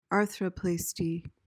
PRONUNCIATION:
(AR-thruh-plas-tee)